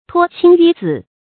拖青紆紫 注音： ㄊㄨㄛ ㄑㄧㄥ ㄧㄩ ㄗㄧˇ 讀音讀法： 意思解釋： 漢制，諸侯佩帶的印綬為紫色，公卿為青色。